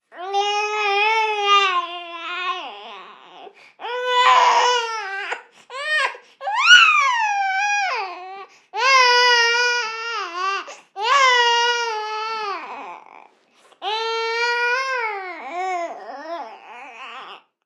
Llanto de un bebé
Voz humana